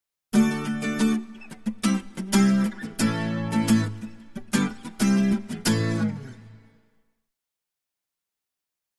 Acoustic Guitar - Happy Rhythm Type 1, Version B